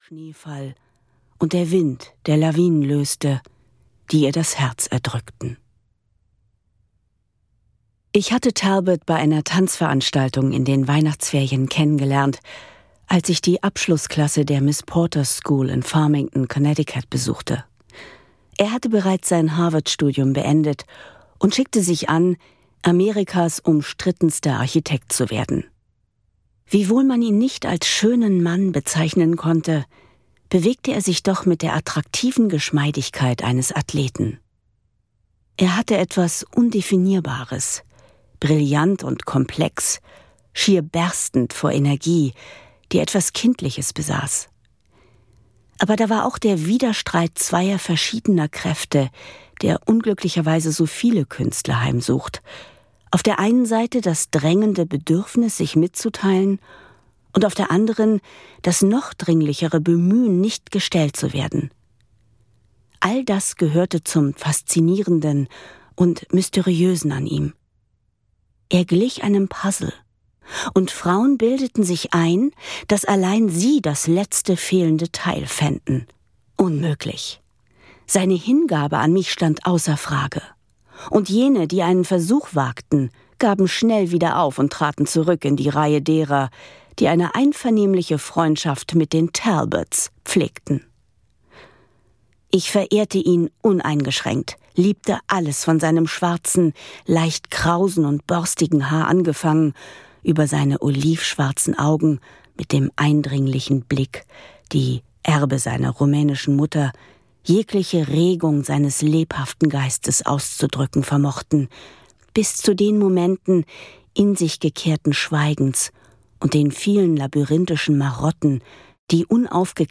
Die Bienenkönigin - Gloria Vanderbilt - Hörbuch